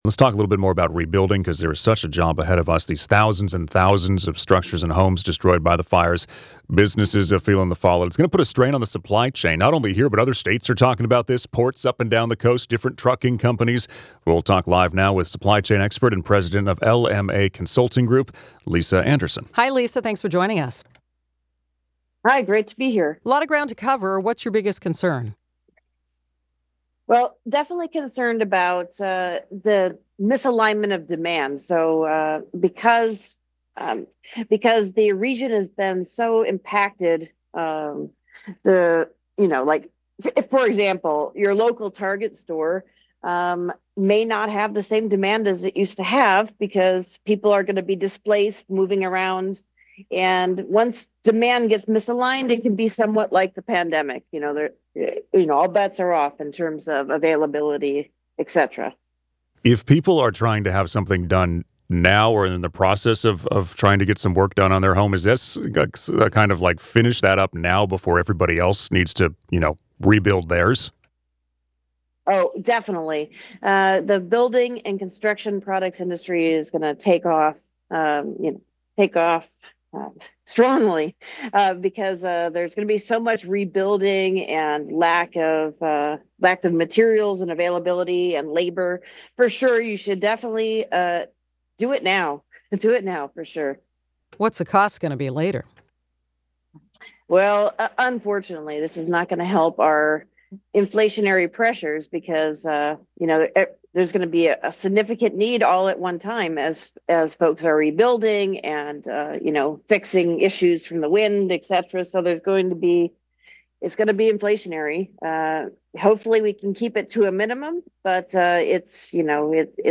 KNX News interview.